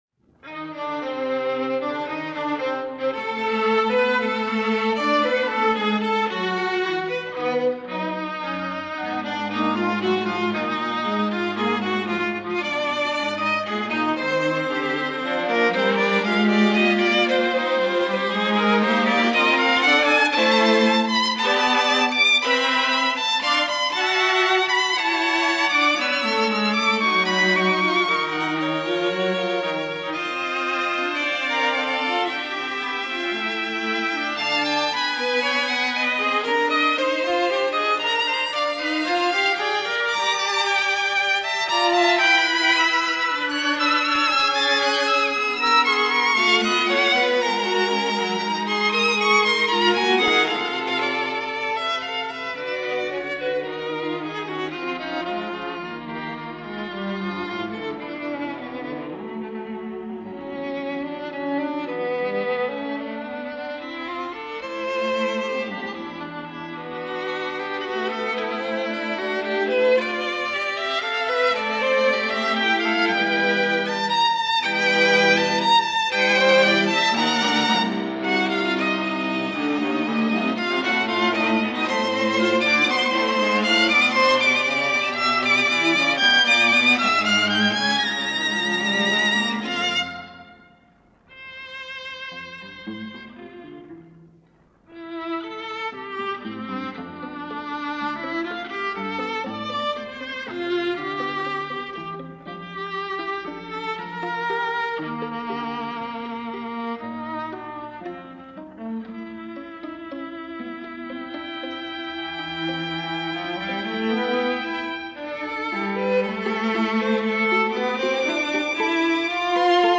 More 20th Century music from Canada this week.
World Premier performance
being more contemplative and serene